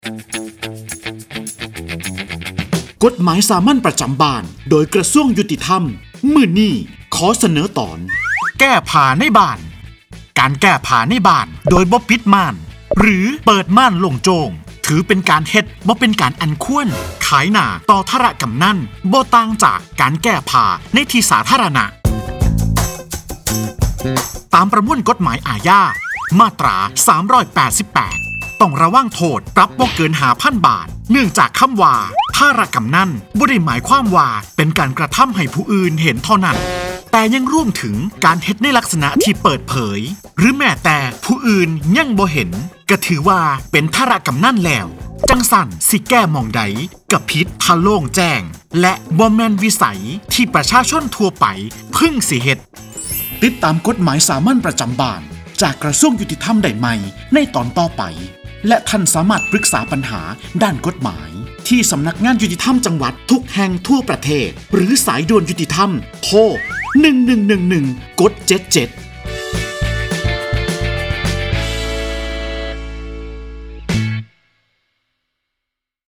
กฎหมายสามัญประจำบ้าน ฉบับภาษาท้องถิ่น ภาคอีสาน ตอนเปลื้องผ้าในบ้าน
ลักษณะของสื่อ :   คลิปเสียง, บรรยาย